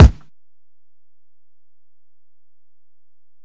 Kick (40).wav